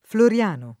florL#no] pers. m. — più diffuso il femm. -na — raro Floreano [flore#no] (meno raro il femm. -na), presente anche nel top. San Floreano (Friuli: presso Gemona), unico in questa forma di fronte ai vari San Floriano (Ven., V. G., A. A.; anche Friuli: presso Pordenone) — sim. i cogn. Florean [flore#n] (in qualche famiglia alterato in fl0rean), Floreani, -no, Florian [florL#n], Floriani, -no